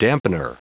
Prononciation du mot dampener en anglais (fichier audio)
Prononciation du mot : dampener